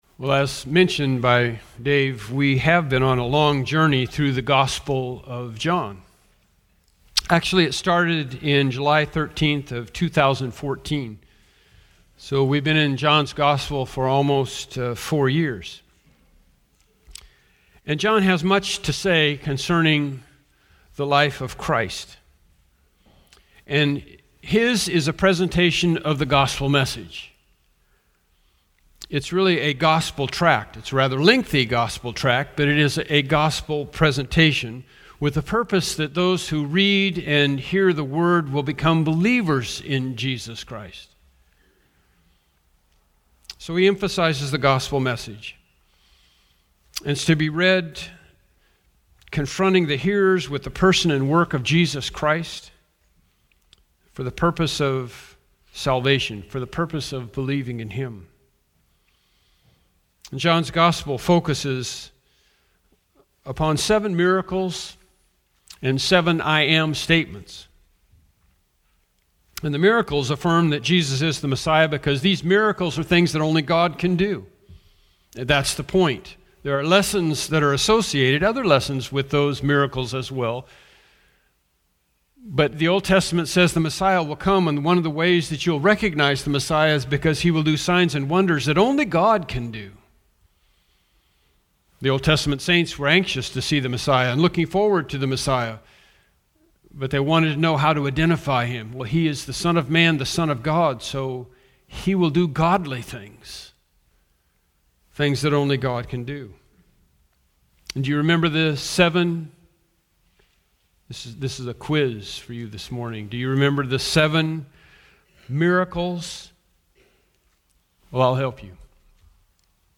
Bible Text: John 21:24-25 | Preacher